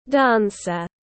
Dancer /ˈdænsər/